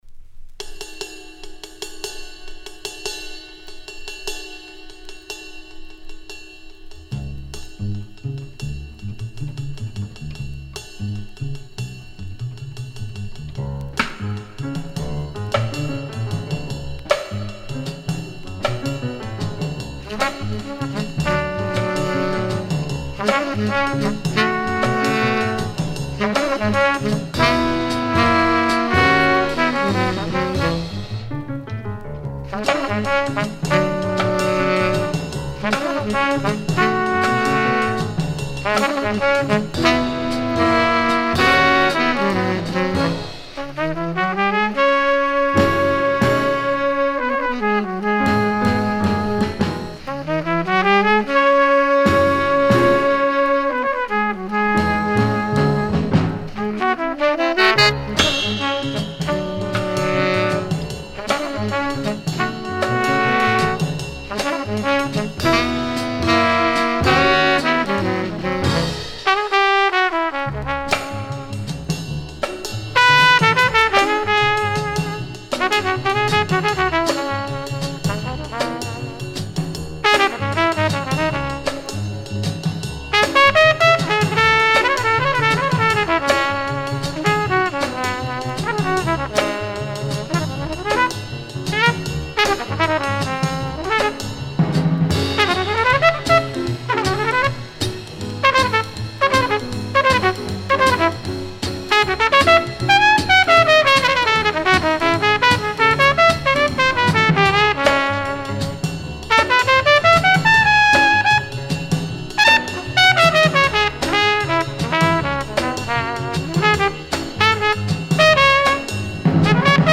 Recorded February 23-25, 1955 at Capitol Studios NYC